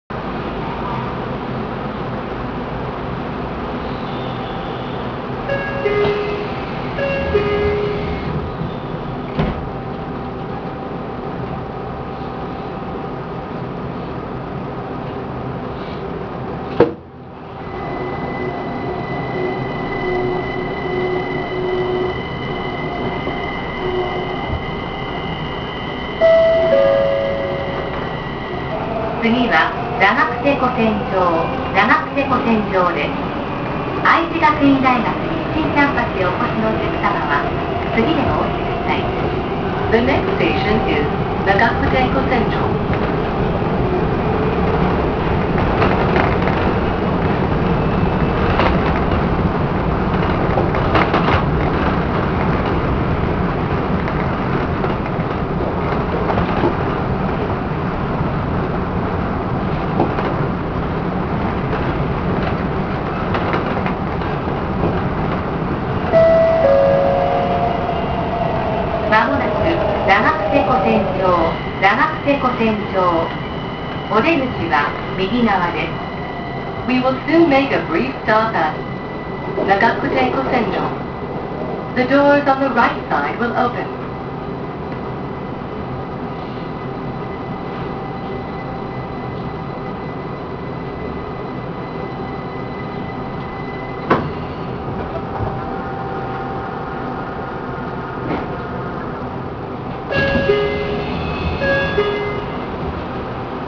線路のジョイント音が無いのは不思議な感覚です。
・100形走行音
VVVFの音もドアの音もありがちなものですが、やはりジョイント音が無いのが不思議な感じです。